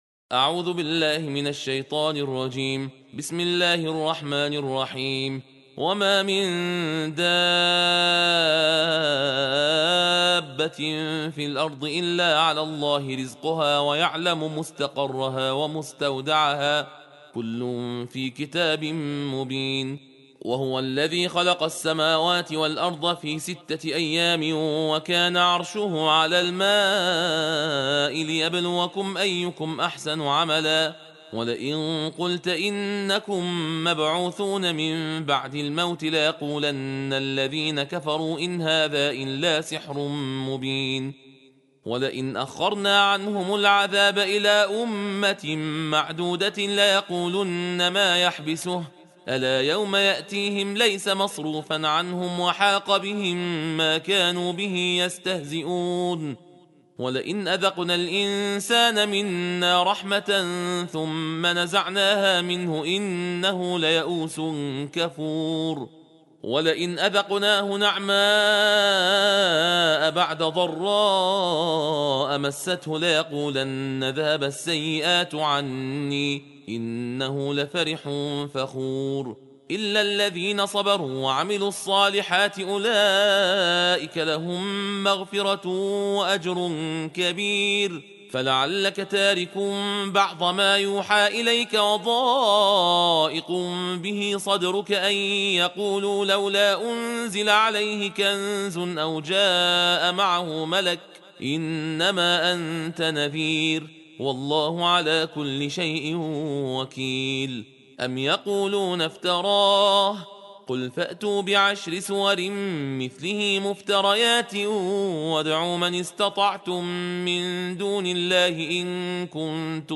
جزء دوازدهم قرآن صوتی تندخوانی با متن و ترجمه درشت جز 12 - ستاره